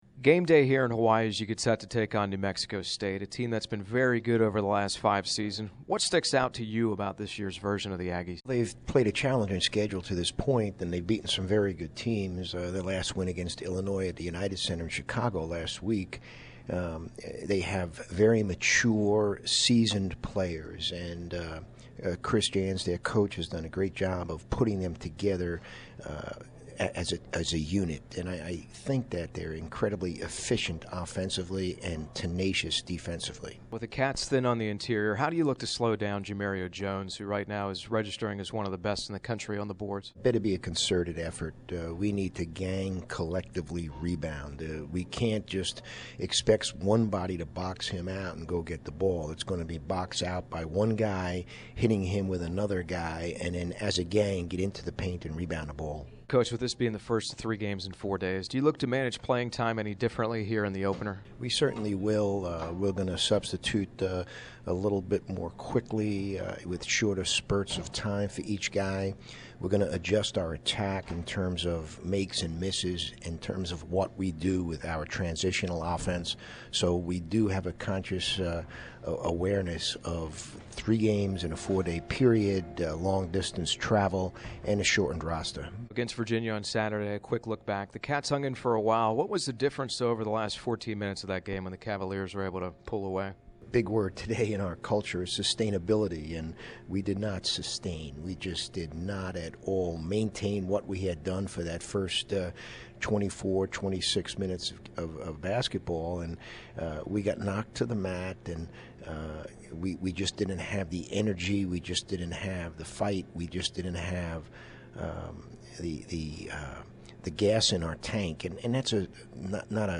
Pregame Interview
Pregame New Mexico State.MP3